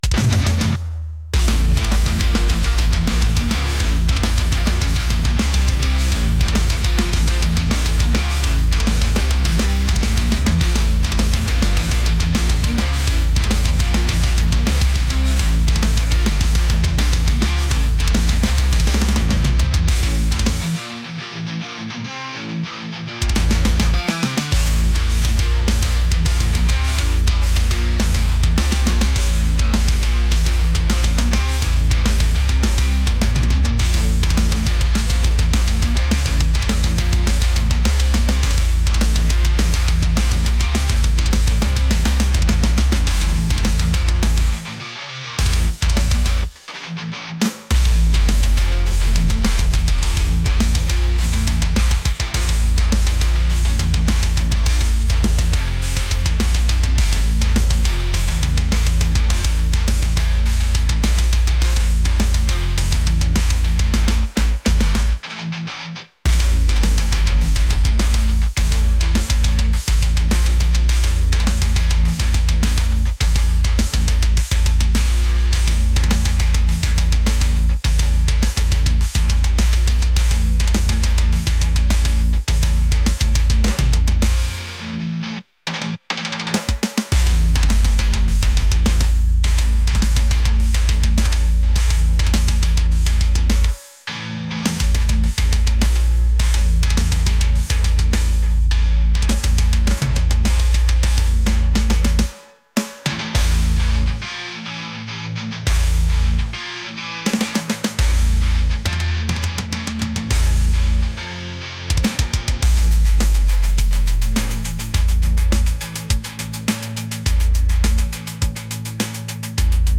aggressive | metal | heavy